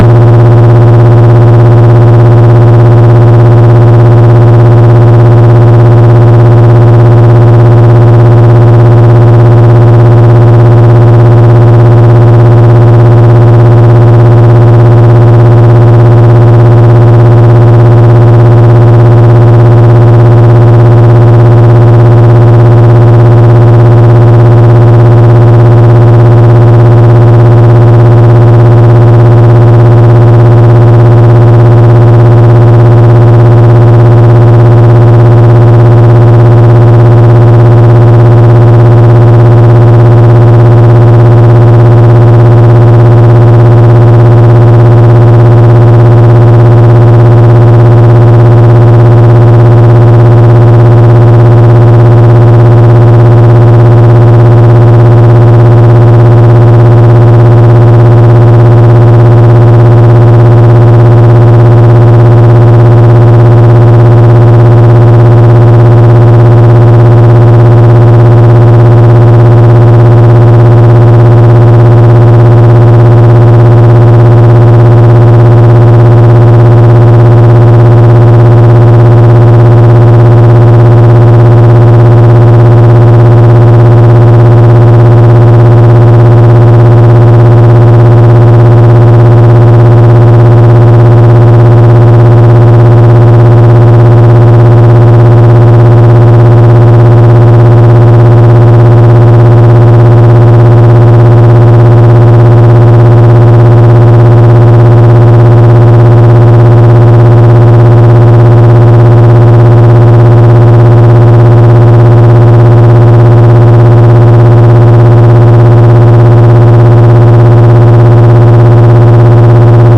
Som de proteção de privacidade